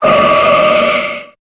Cri de Gardevoir dans Pokémon Épée et Bouclier.